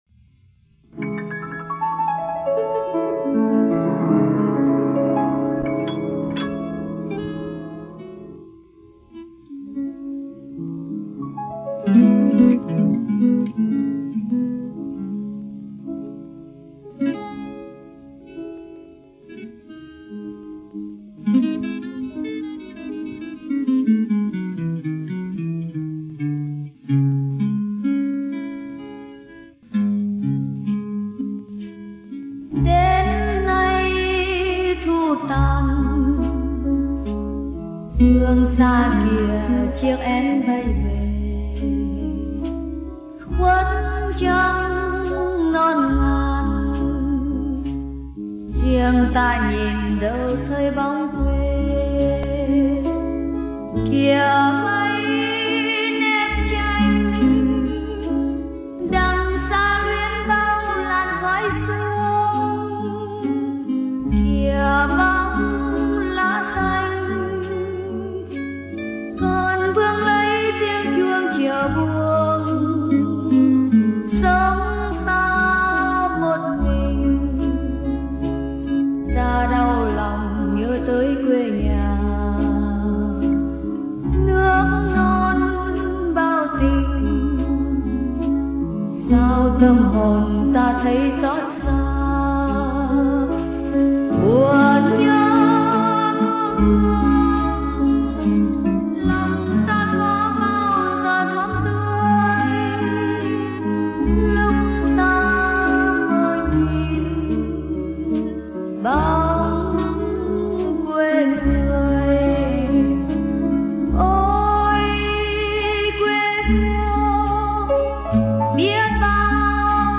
nhịp ba (valse)